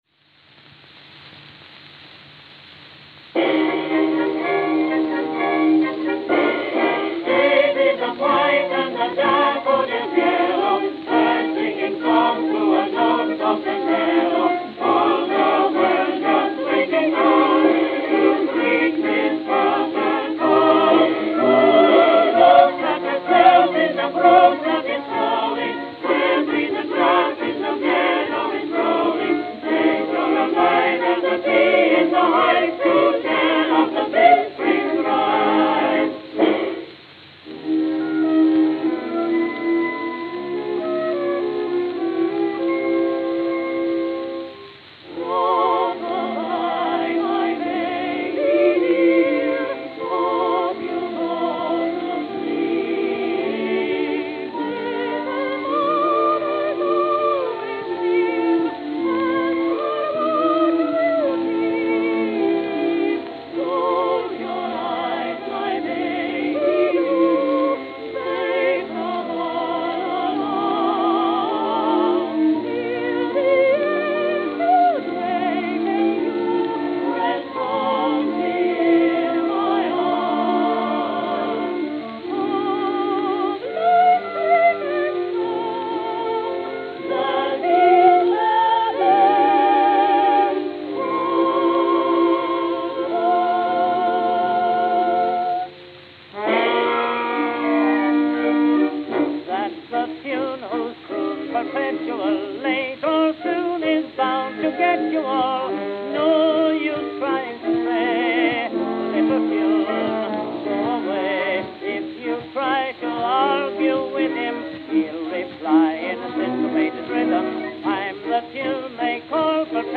Camden, New Jersey Camden, New Jersey